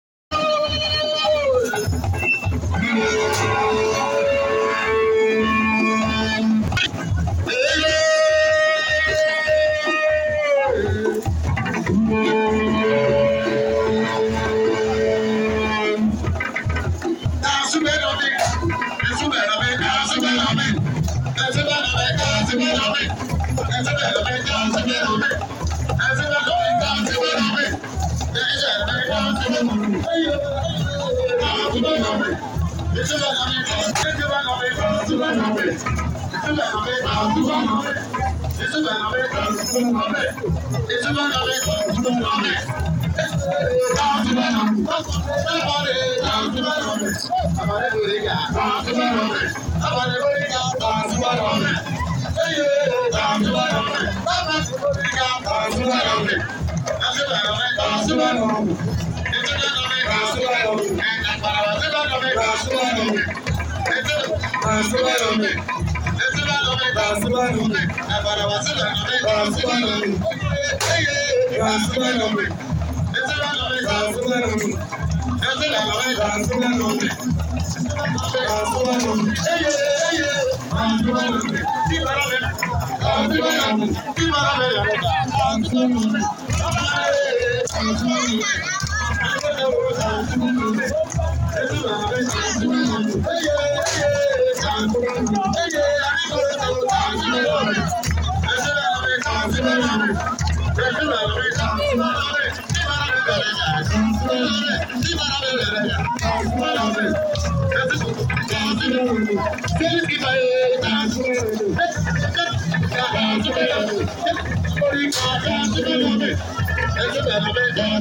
happening live today at Ogoloma Market square